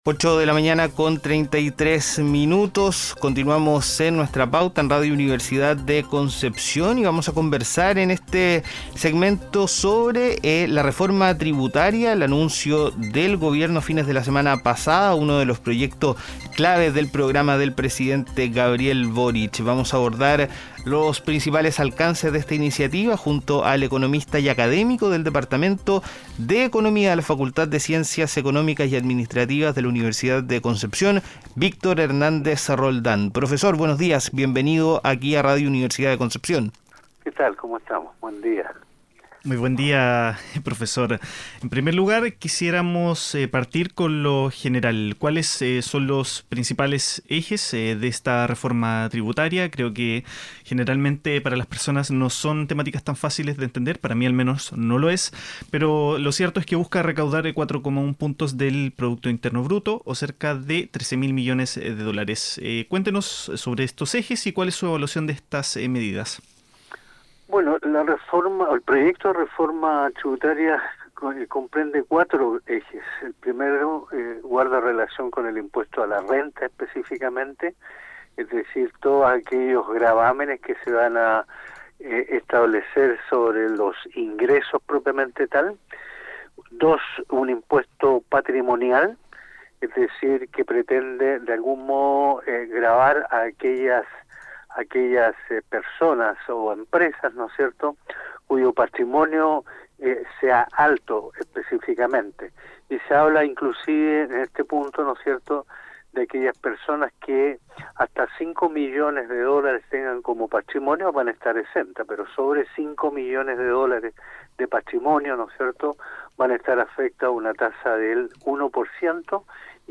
en Nuestra Pauta conversamos con el economista